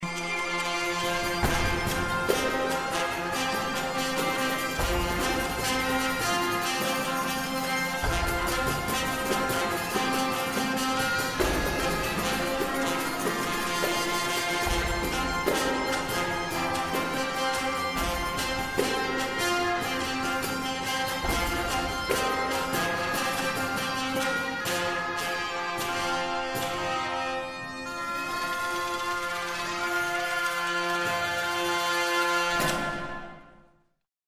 I cut it out of a podcast about De Machaut, medieval music, ars nova etc. but they did not include a playlist. It might be a secular work by Guillaume de Machaut or possibly Philippe de Vitry, but even if I know the composer I would...